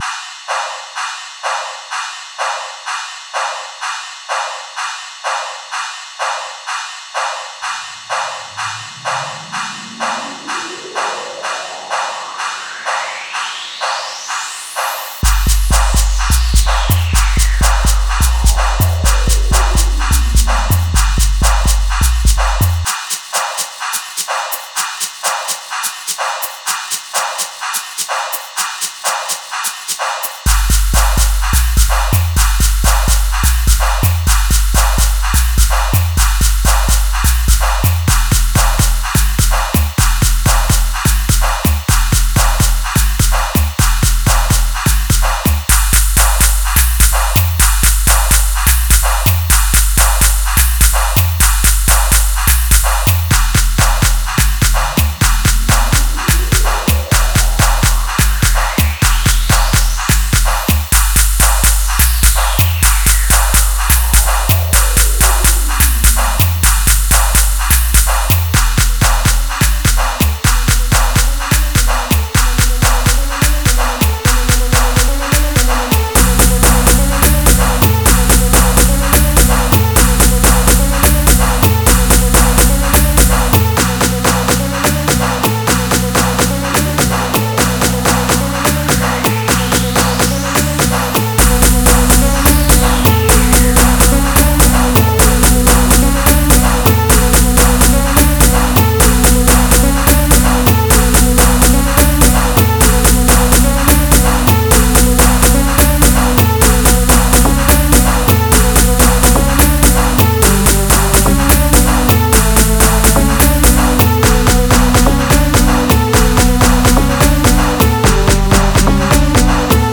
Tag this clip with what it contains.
Genre : Gqom